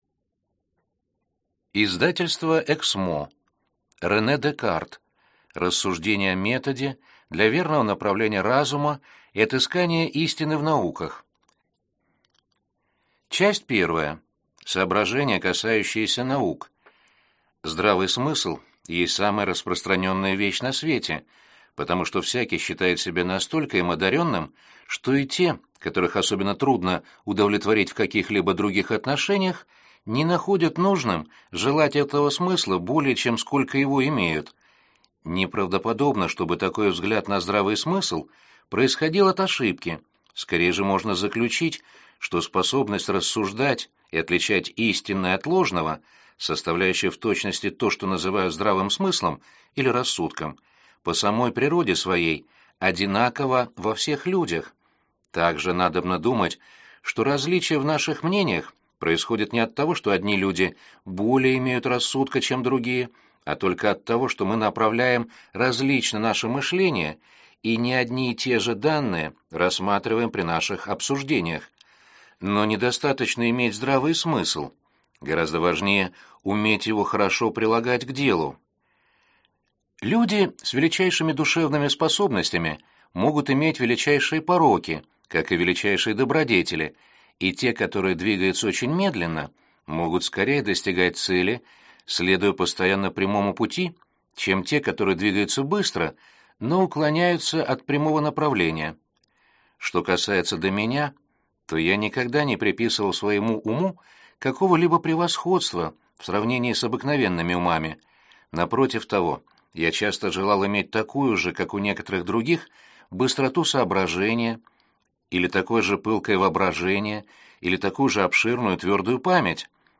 Аудиокнига Рассуждение о методе | Библиотека аудиокниг